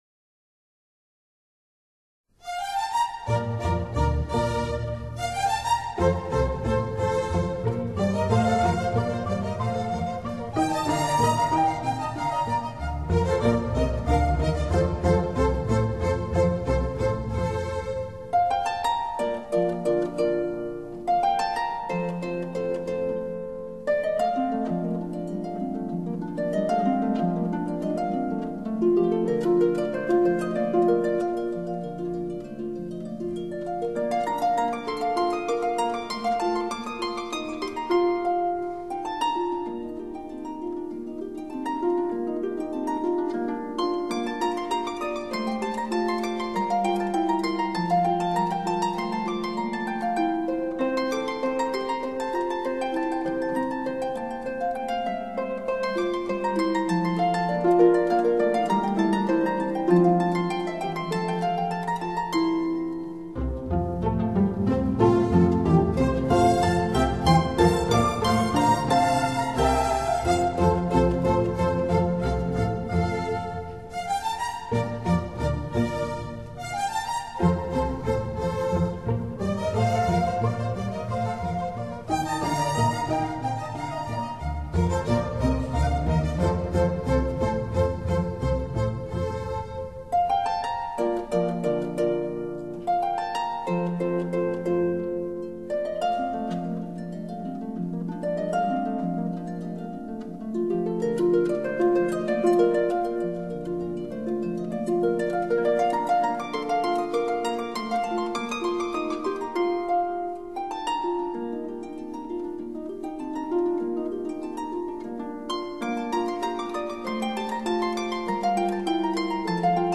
專輯豎琴名演奏家介紹